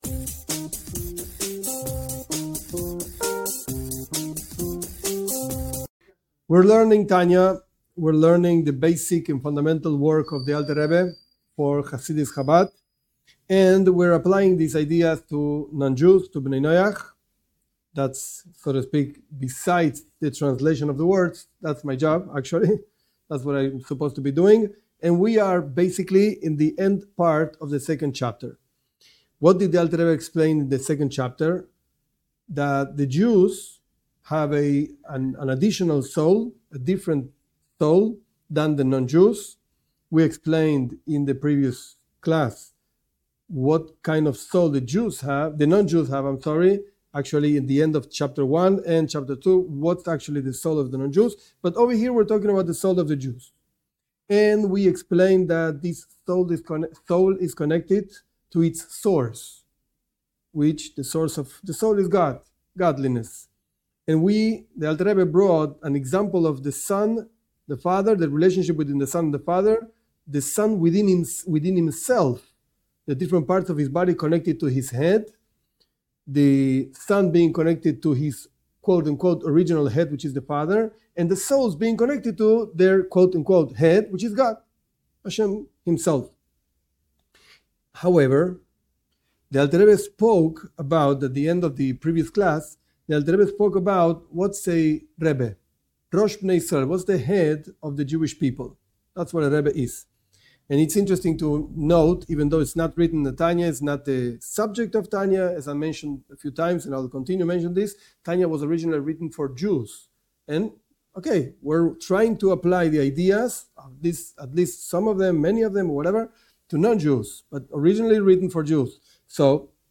This class teaches Tanya, Book of Beinonim (intermediate people).